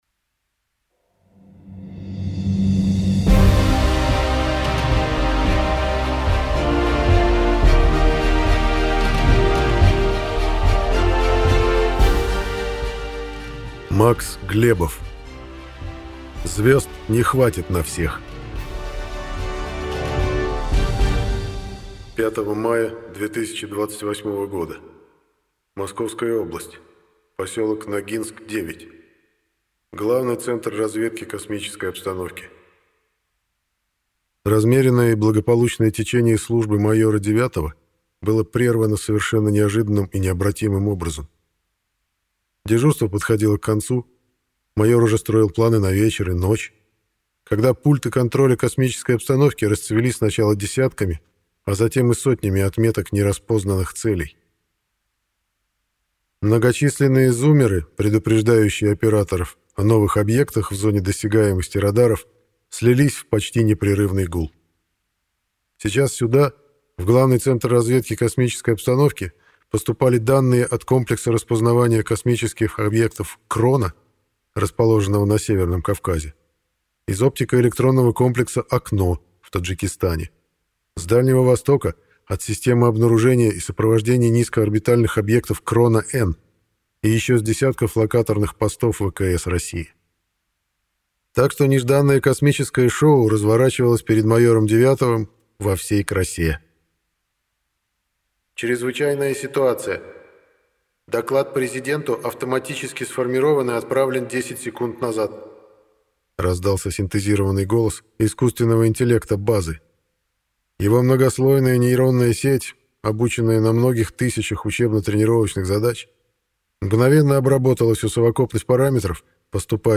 Аудиокнига Звезд не хватит на всех | Библиотека аудиокниг